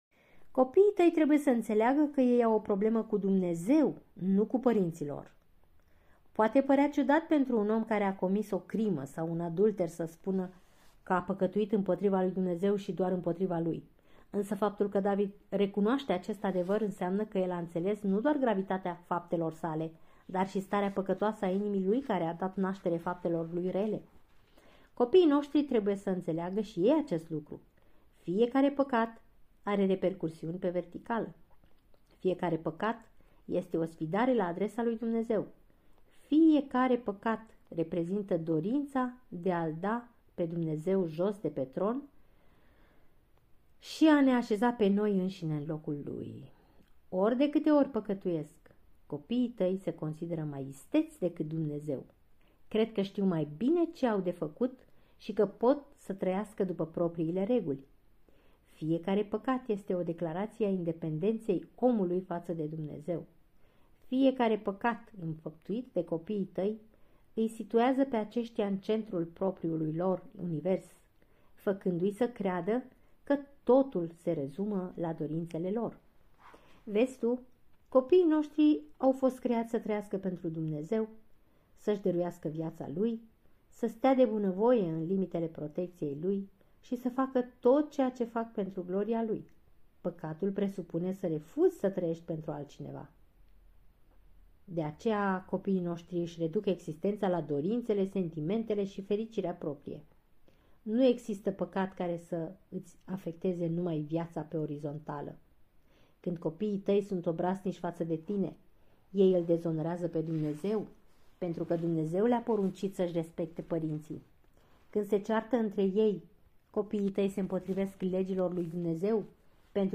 Capitolul este citit